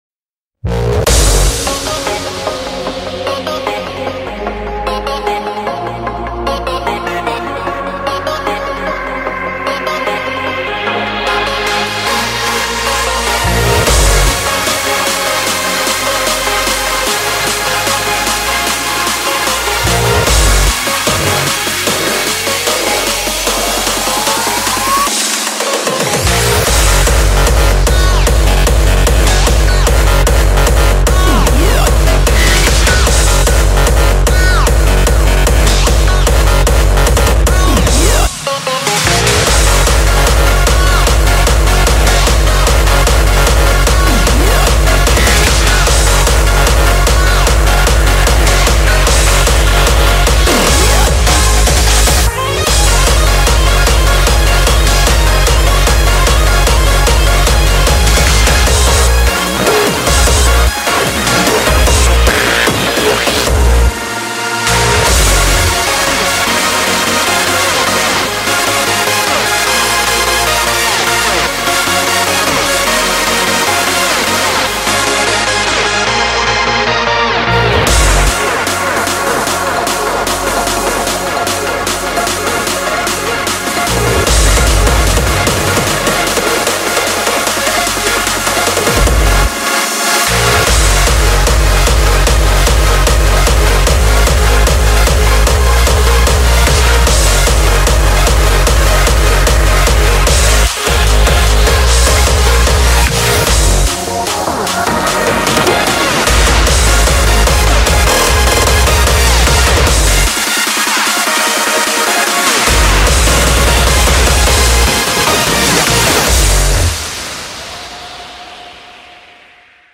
BPM150
Audio QualityPerfect (High Quality)
GENRE: HARDSTYLE